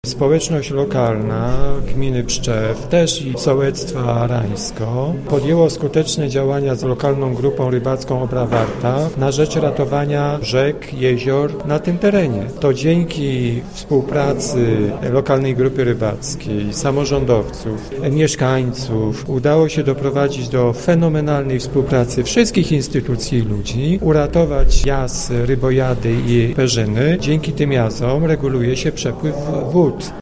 W wydarzeniu uczestniczył poseł Jacek Kurzępa, który zauważa, że dla mieszkańców gminy Pszczew jest to ważny punkt, ponieważ upamiętnia rozpoczęcie działań związanych z ochroną rzeki Obry i przyległych jezior: